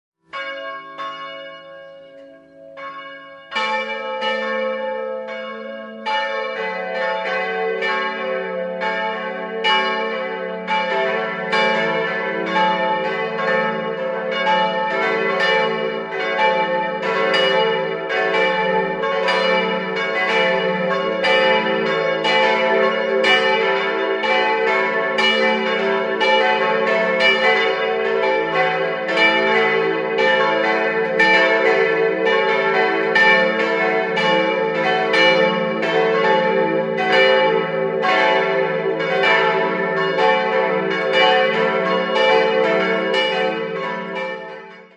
Mitten im Ort erhebt sich die Pfarrkirche St. Leodegar, eine ursprünglich romanische Anlage, die im 17. Jahrhundert neu erbaut wurde. Im Inneren erfreuen den Besucher die drei prächtigen frühbarocken Altäre. 4-stimmiges Geläute: e'-fis'-a'-cis'' Nähere Daten liegen nicht vor.